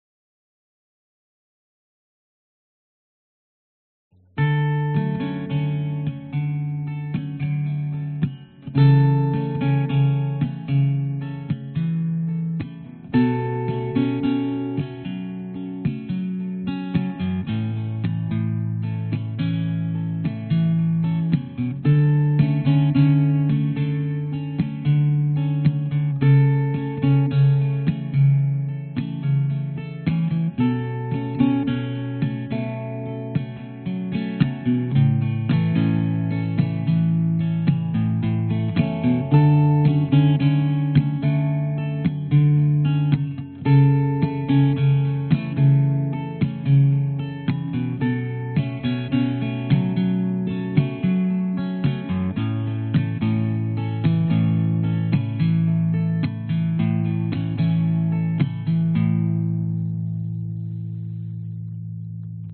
描述：声学GTR琶音，用2个通道录制，一个DI和一个电容话筒，加入iZotope合金+垃圾。
Tag: 原声 寒冷 吉他